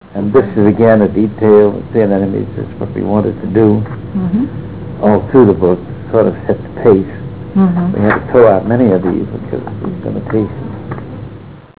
95Kb Ulaw Soundfile Hear Ansel Adams discuss this photo: [95Kb Ulaw Soundfile]